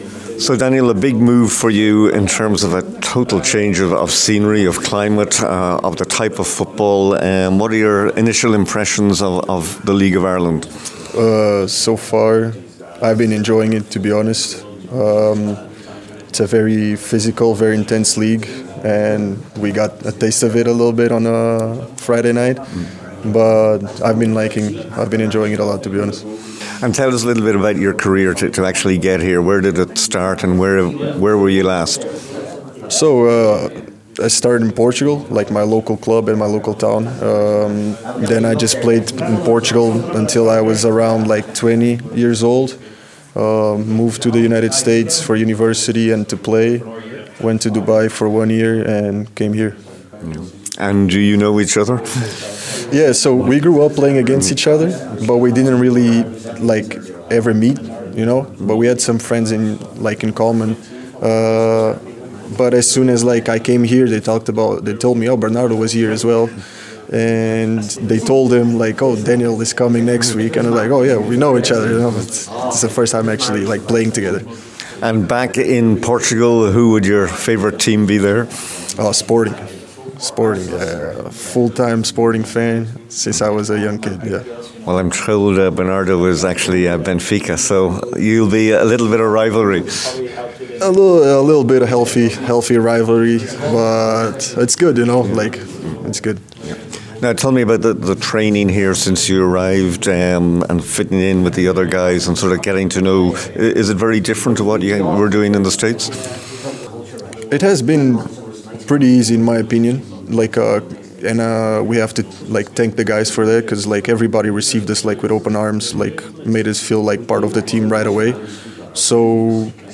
At Monday’s press conference at Jackson’s Hotel in Ballybofey